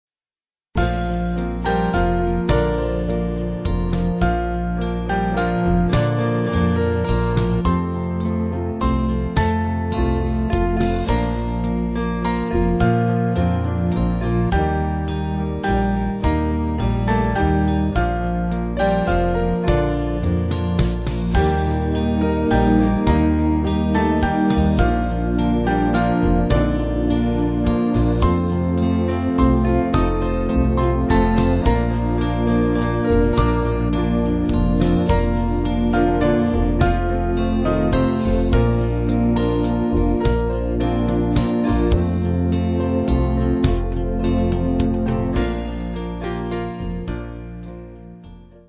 Small Band
Quieter